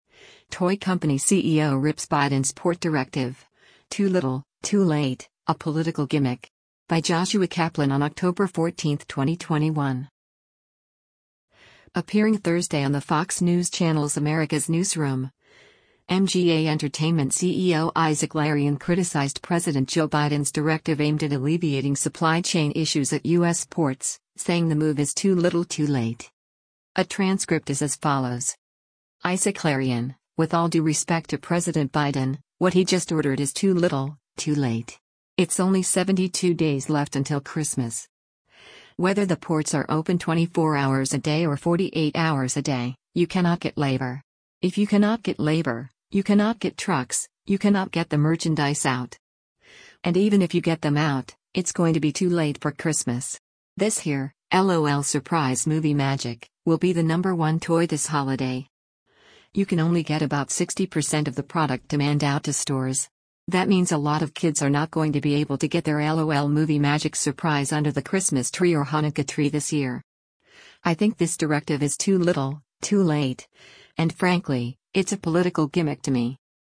Appearing Thursday on the Fox News Channel’s America’s Newsroom